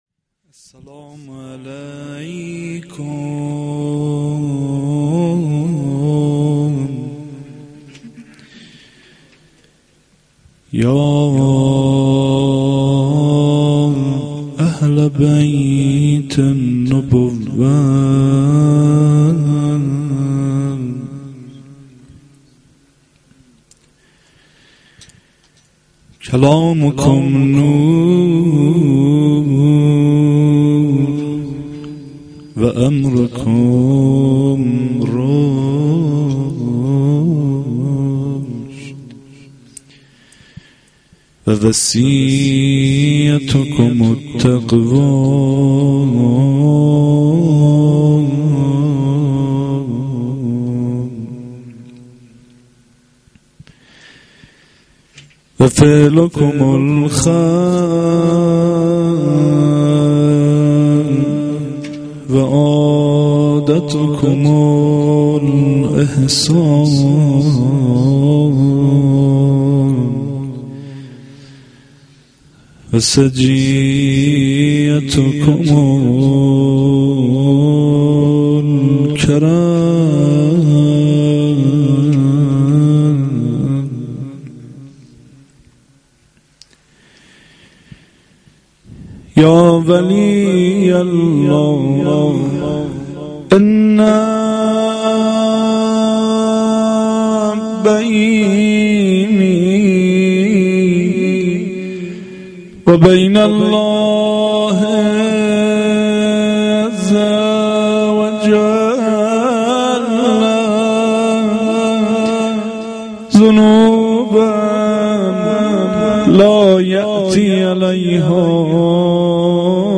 fa-avval-92-rozeh.mp3